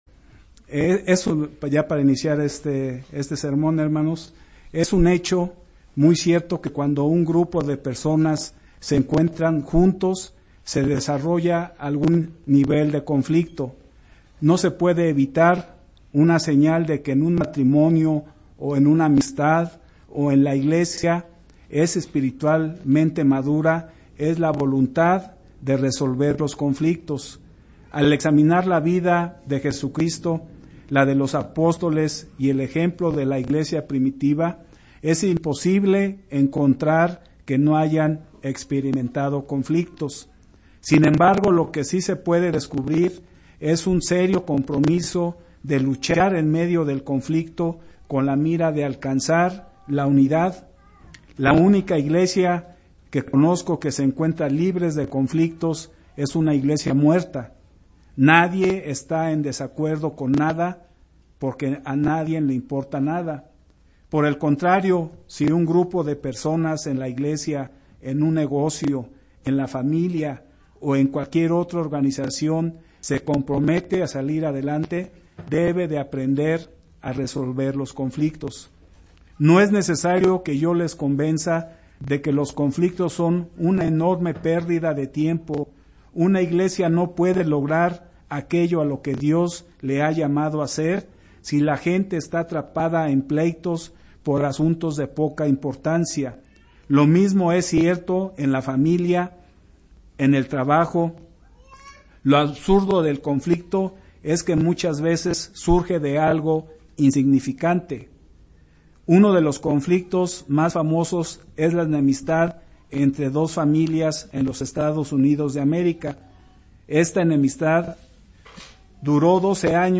Given in Ciudad de México